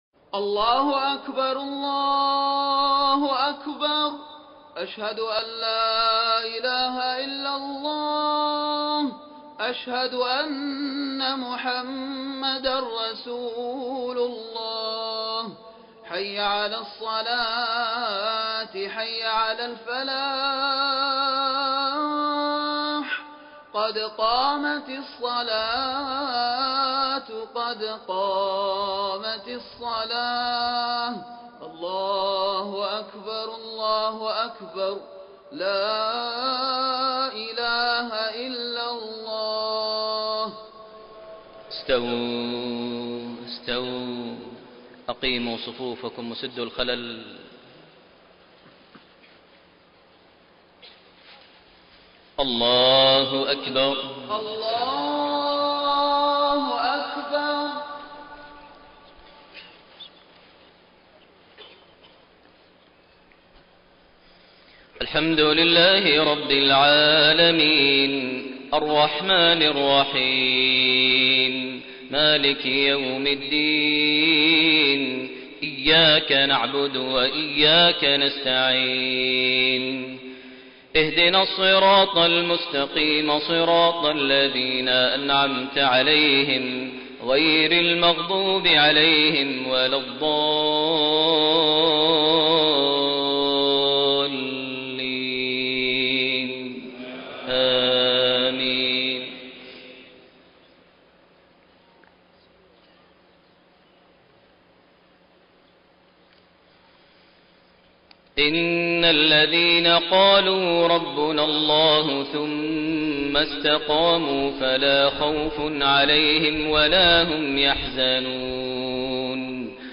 صلاة المغرب 24 جمادى الأولى 1433هـ من سورة الأحقاف 13-19 > 1433 هـ > الفروض - تلاوات ماهر المعيقلي